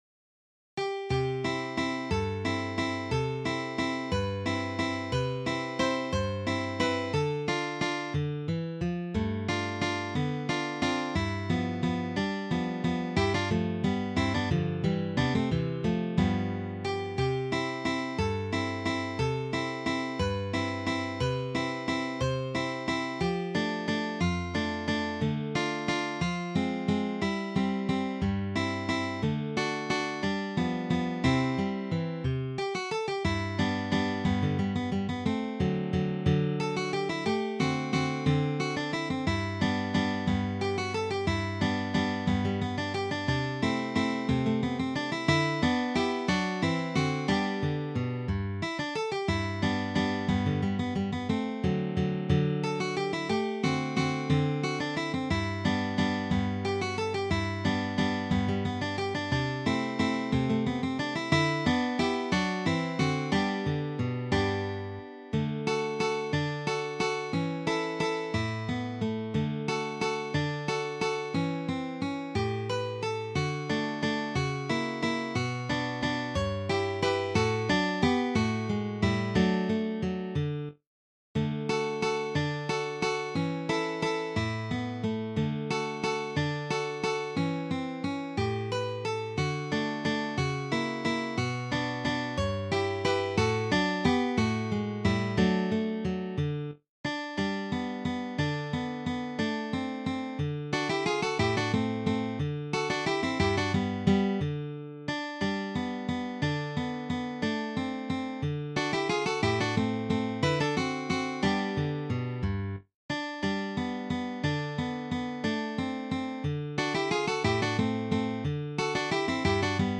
arranged for three guitars